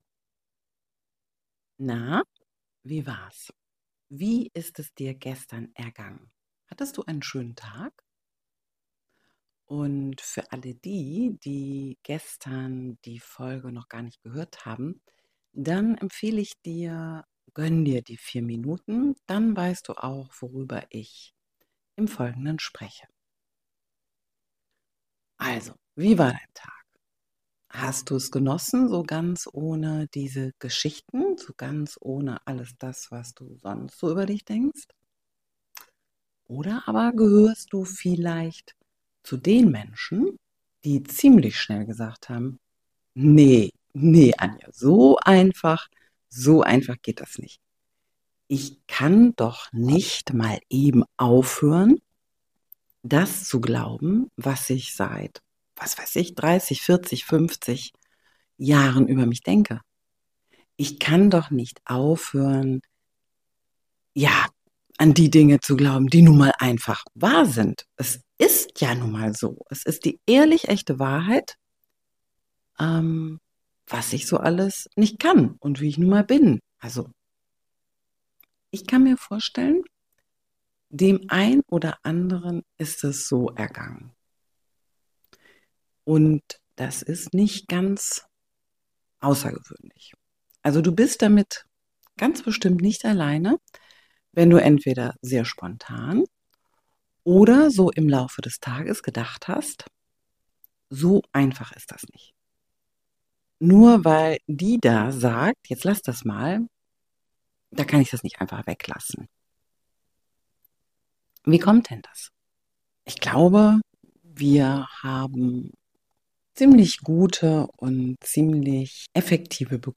bisschen geschnitten, technisch nicht sauber -- aber echt. :-)